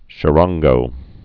(chə-ränggō)